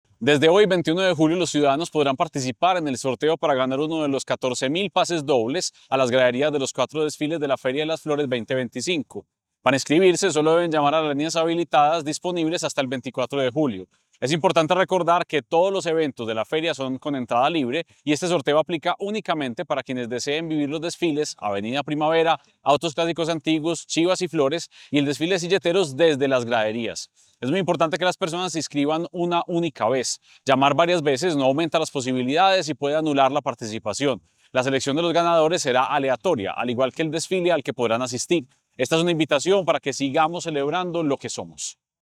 Declaraciones del secretario de Cultura, Santiago Silva Jaramillo.